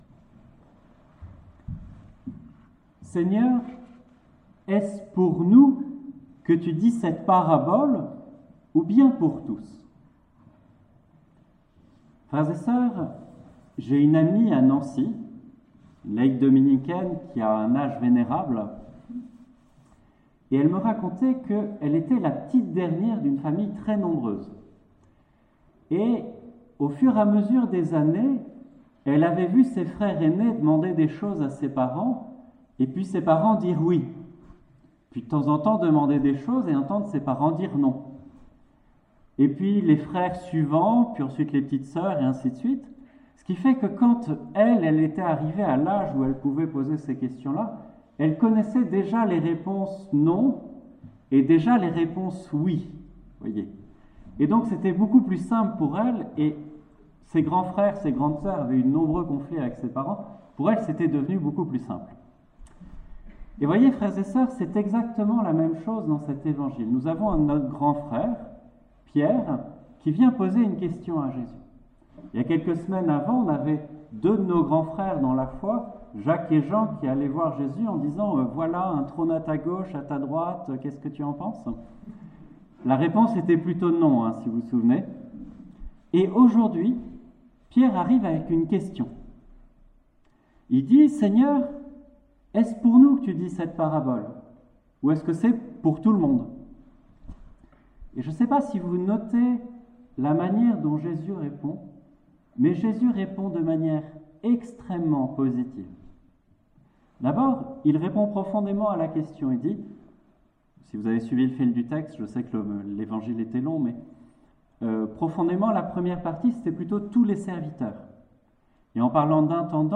Prédications 2018-2019 C